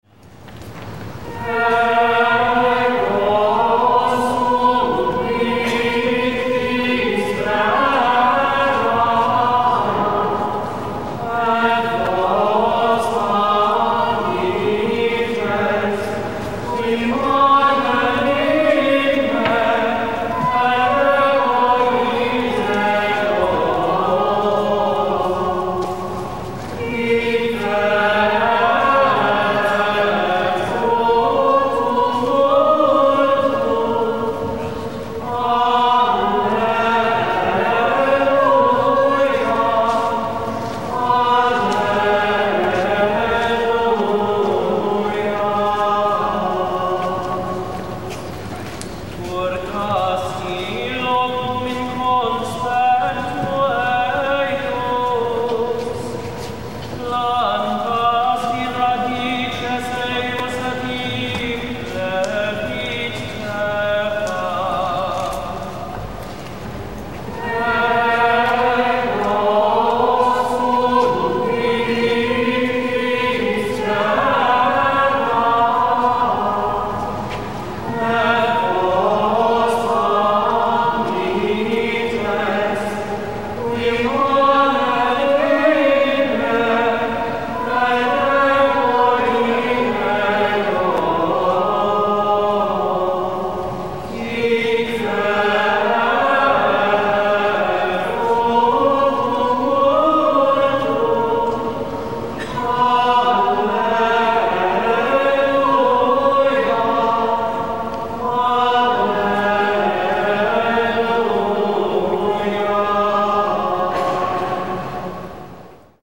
Here are audio clips of some Gregorian chant (excerpts in each case) from the abbey, last Sunday’s Fifth Sunday of Easter, with some musings.
Ego sum vitis vera (“I am the true vine”) is the communio of the day.